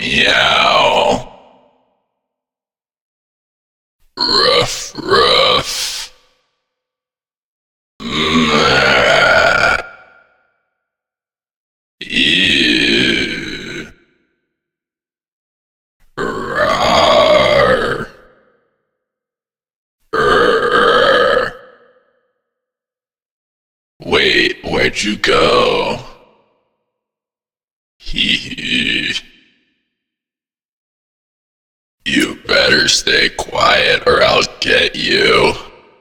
Figure_grawl.ogg